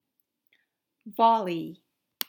volley.m4a